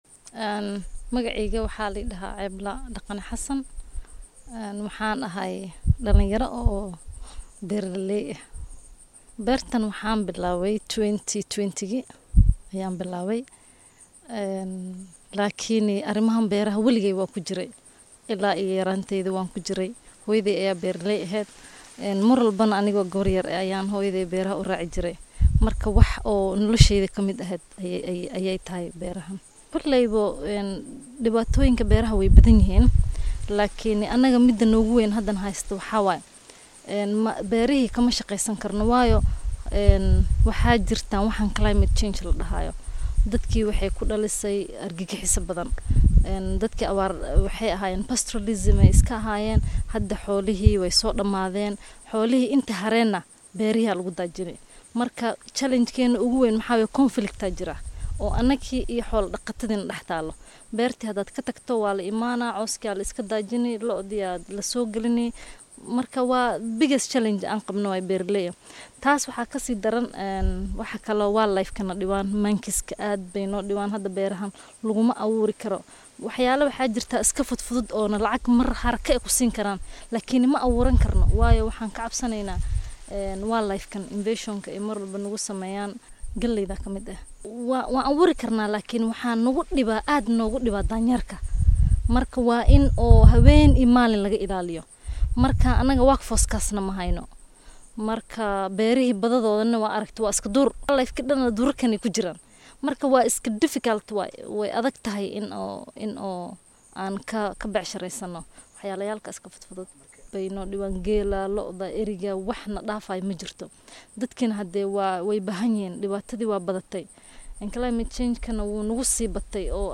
Beeralayda tulada Korkora ee ismaamulka Garissa ayaa inooga warramay caqabadaha haysta iyo sida ay u shaqeeyaan.